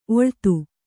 ♪ oḷtu